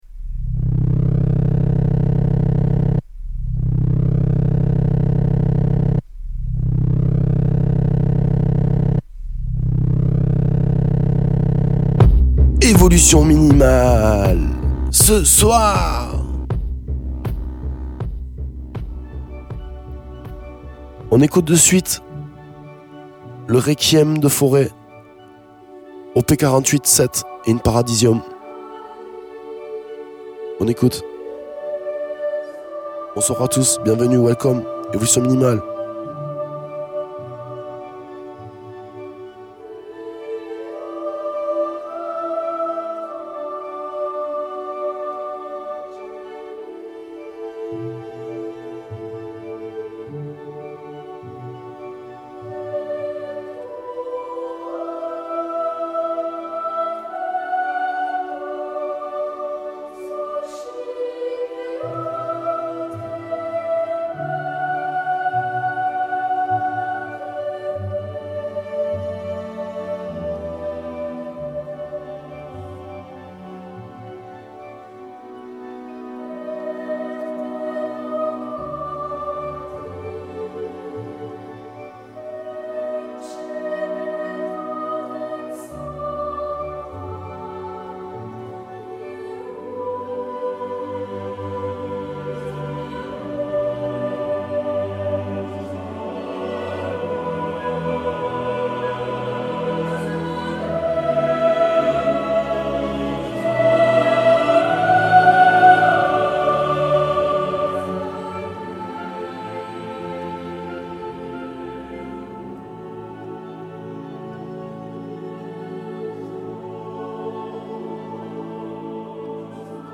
Le retour de l’afro-beat!